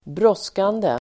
Uttal: [²br'ås:kande]